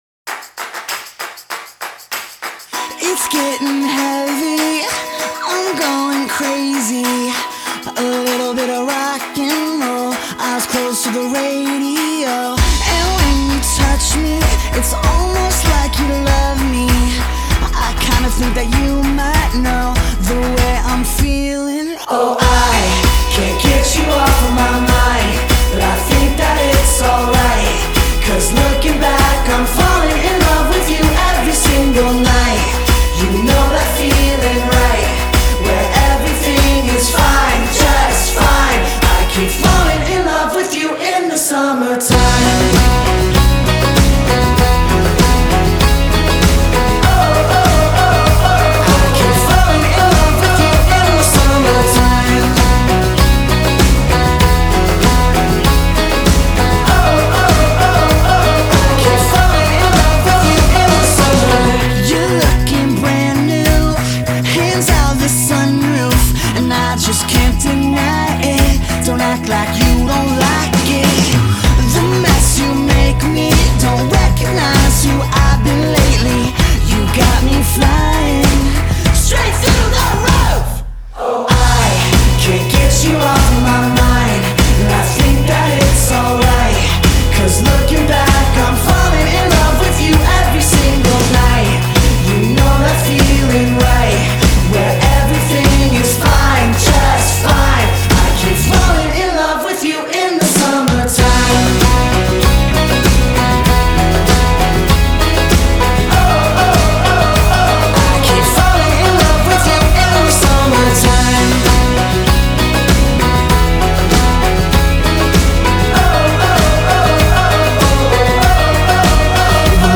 small-stringed instruments and a bit of swing
catchy single